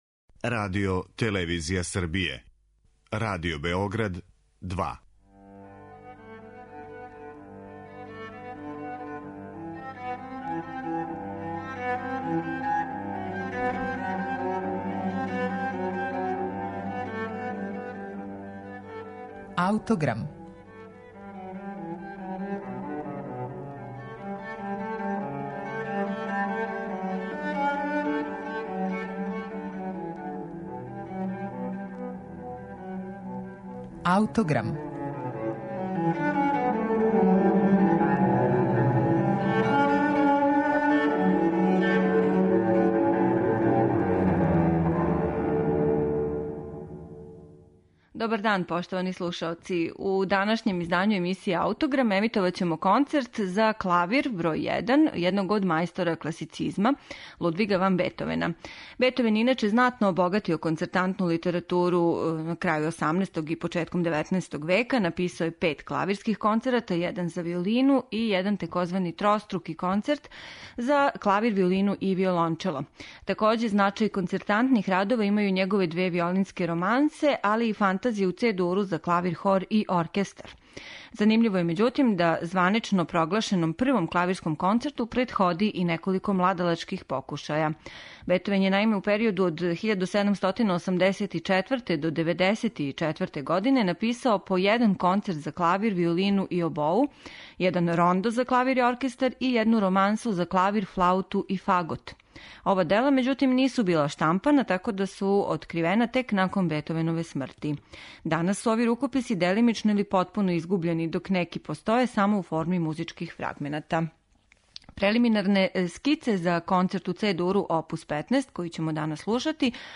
Концерт за клавир број један у Це-дуру оп. 15, једног од мајстора класицизма - Лудвига ван Бетовена, био је завршен 1798. године, када га је у октобру, са великим успехом, први пут у Прагу извео сам композитор.